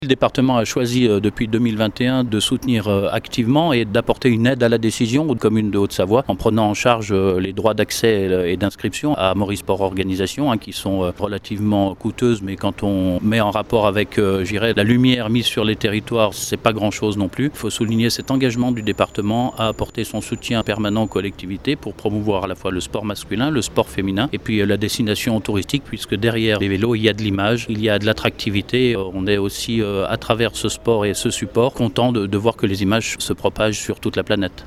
Les explications de Nicolas Rubin, le maire de Chatel et Vice-président du département délegué au sport